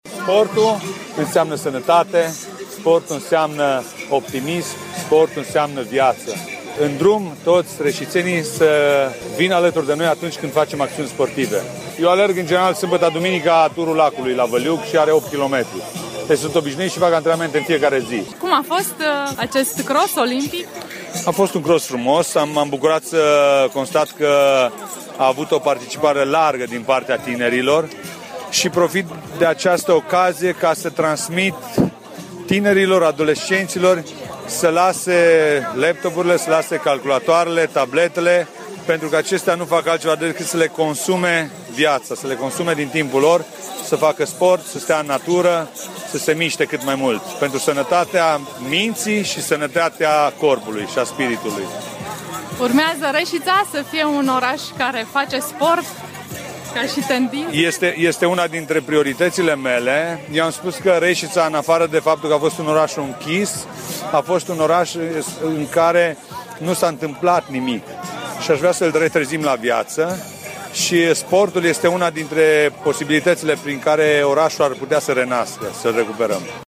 Primarul Ioan Popa consideră că Reșița poate ajunge un oraș al sportului: